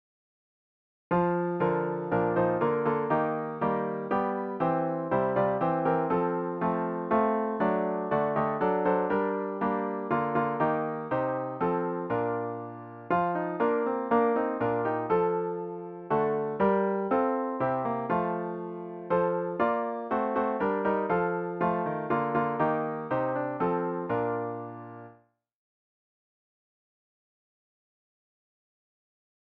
Fichiers pour répéter :
We wish you a merry christmas 4 voix